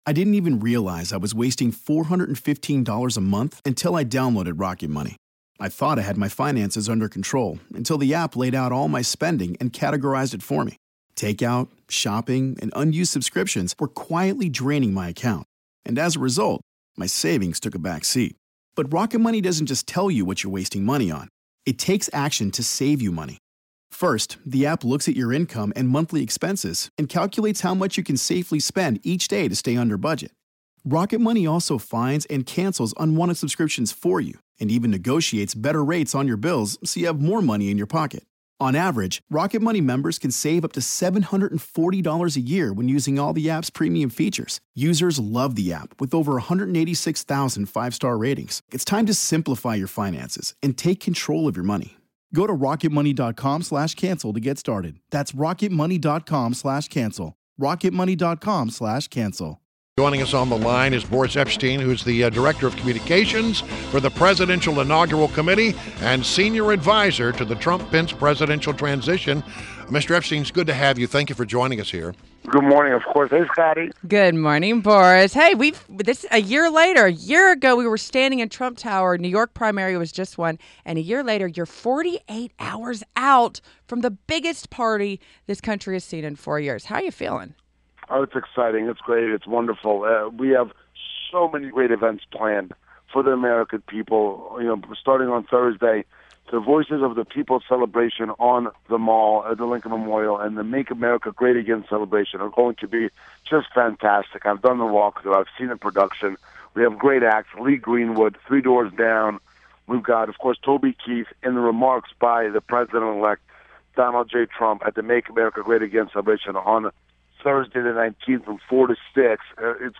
WMAL Interview - BORIS EPSTEYN - 01.18.17